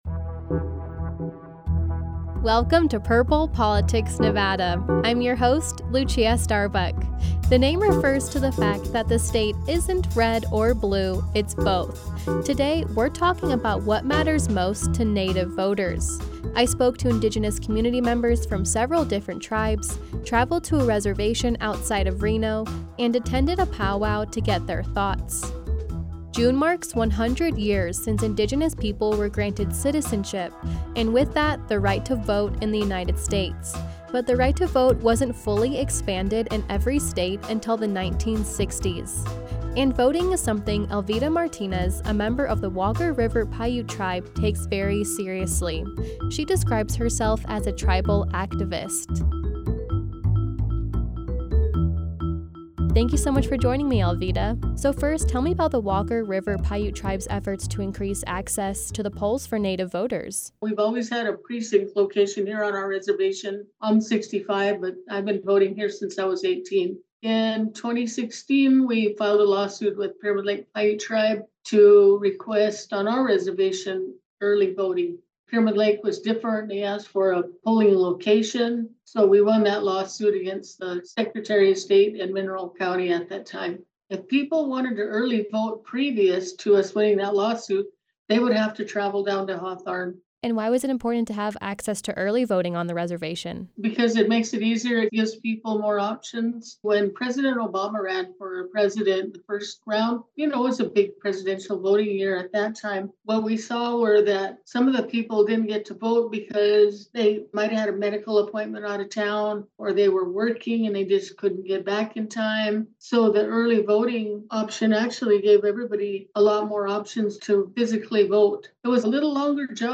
spoke to Indigenous community members from several different tribes, traveled to a reservation outside of Reno, and attended a powwow to get their thoughts.